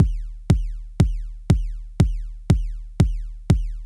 So with some more testing I can also reproduce the “whistle distortion”. Just the default kick 4/4 with a lowpass filter (FREQ 60) and I usef the following compression settings for the sample below:
To make the artefact really audible I resampled the master output, disabled the compressor, then applied a base width filter BASE 86 and a EQ-1 filter to boost with GAIN 63 on FREQ 102:
The sample was made on the Digitakt itself and transfered with the Transfer app.